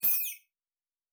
pgs/Assets/Audio/Sci-Fi Sounds/Weapons/Additional Weapon Sounds 4_4.wav at master
Additional Weapon Sounds 4_4.wav